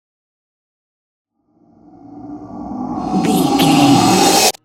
Creature dramatic riser
Sound Effects
In-crescendo
Atonal
scary
ominous
haunting
eerie
roar